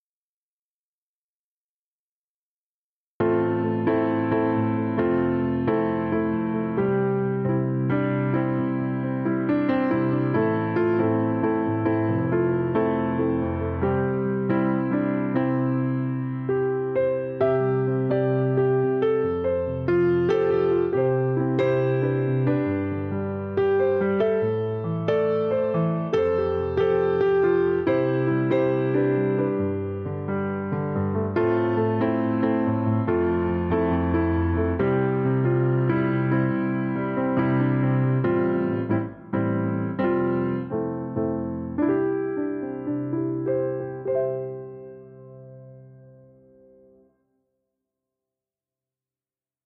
Hintergrundpianistin
Hintergrundmusik bedeutet für mich, einfühlsam Klavier zu spielen und intuitiv auf den jeweiligen Anlass einzugehen.
Einige Hörbeispiele für solche aus dem Moment heraus entstandenen Improvisationen: